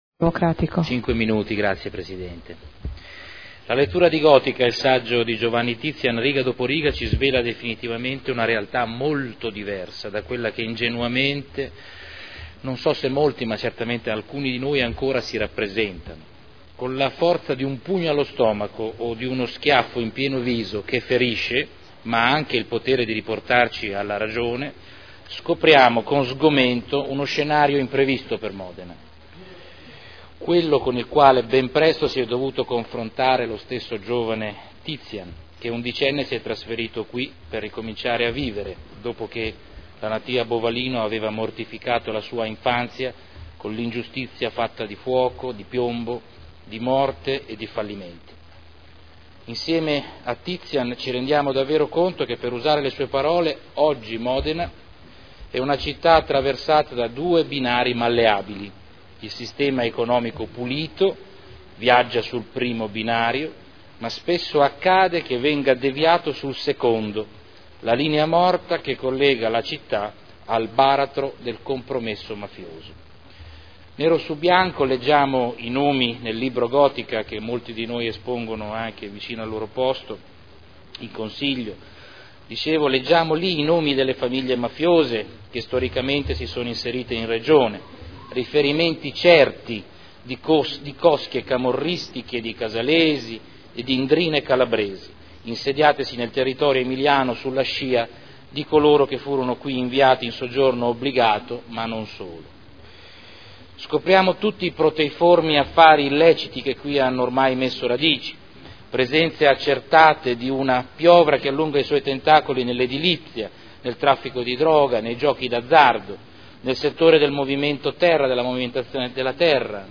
Salvatore Cotrino — Sito Audio Consiglio Comunale